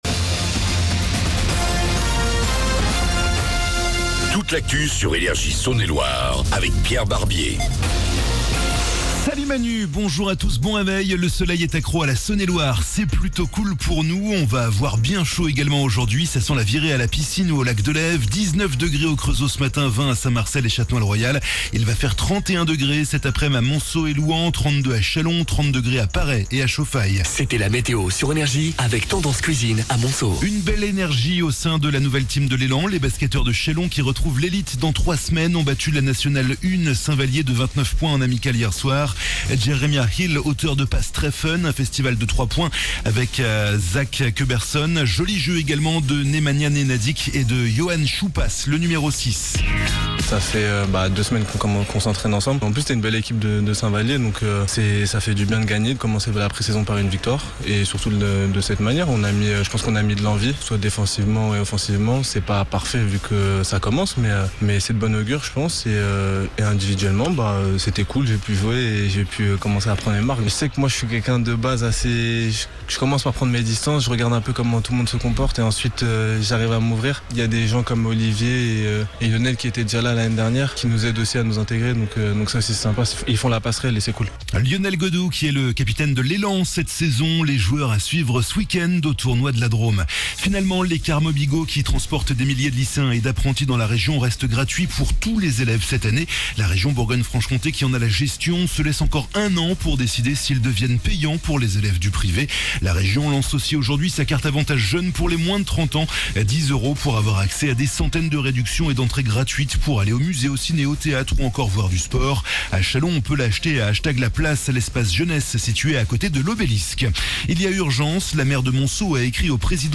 ----- L'INTERVIEW -----